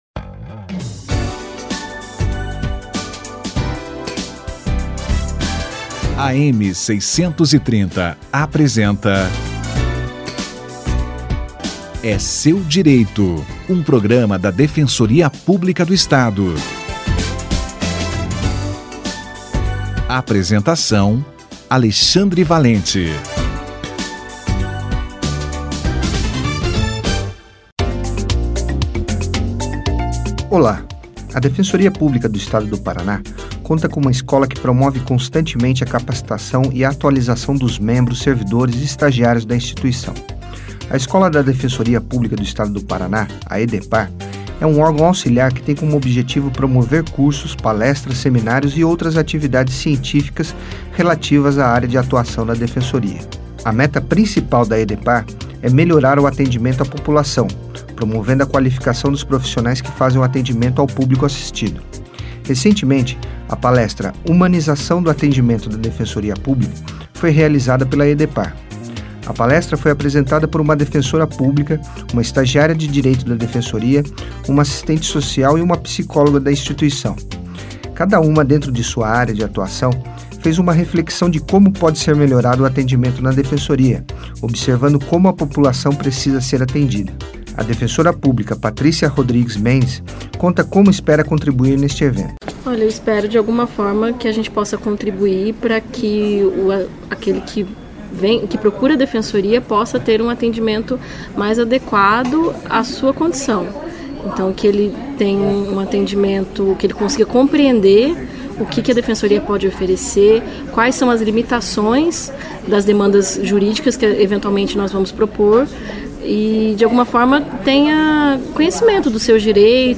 EDEPAR na capacitação dos membros da instituição - Entrevista